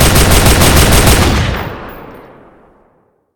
gun.ogg